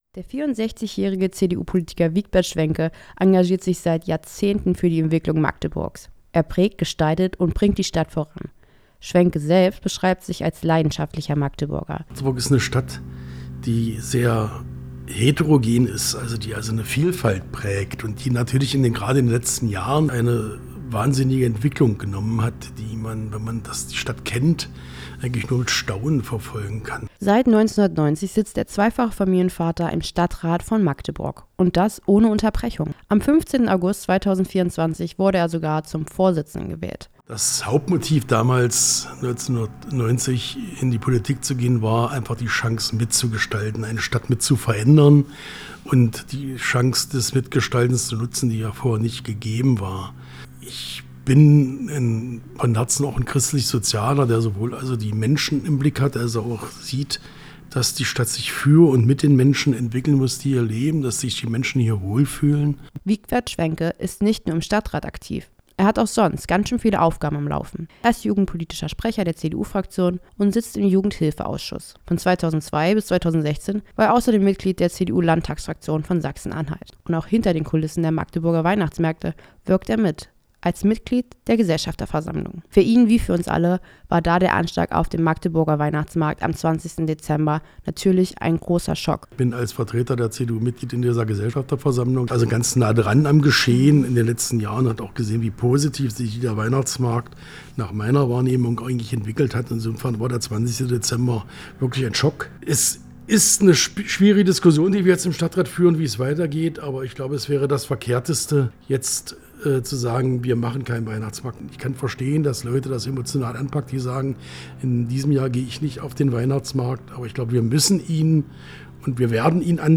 Wigbert Schwenke im Portrait